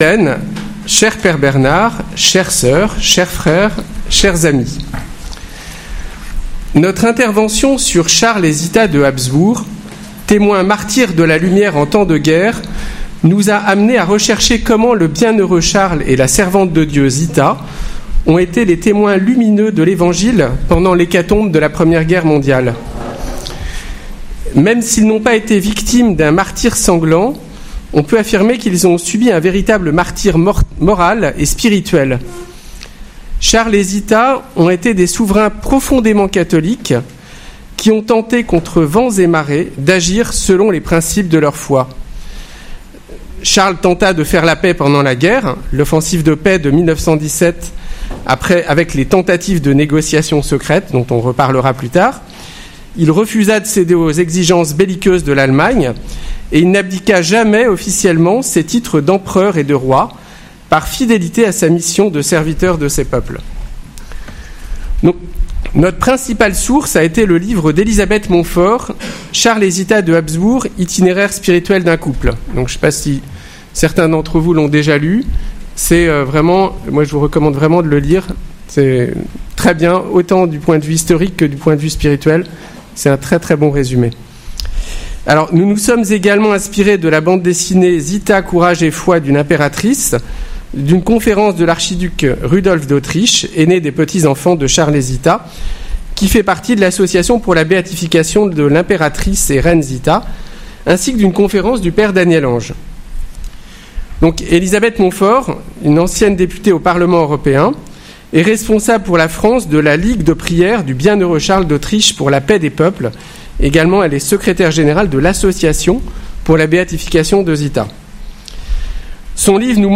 Enseignement/témoignage